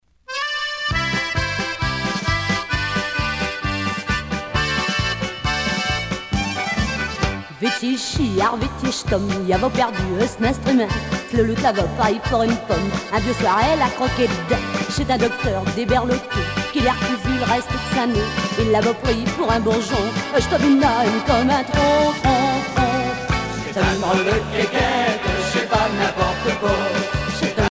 danse : marche